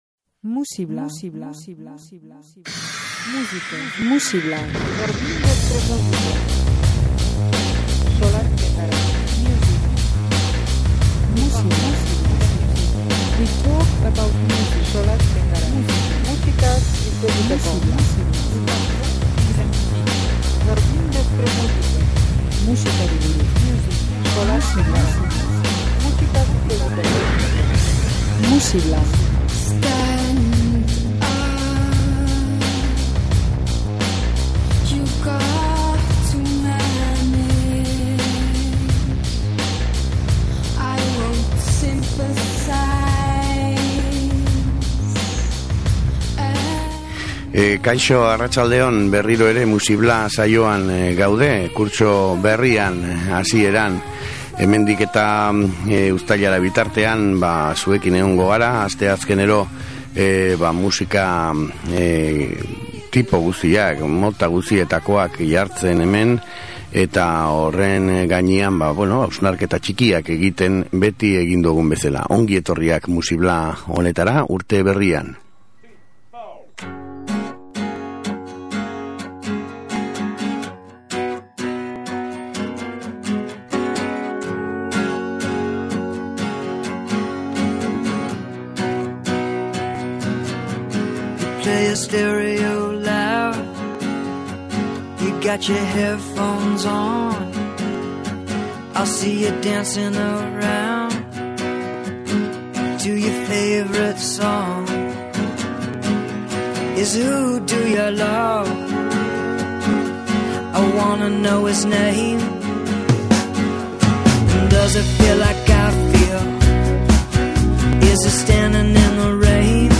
Ikasturte berria hasteko lasai...
Ipar Amerikako sustraietatik egindako musika.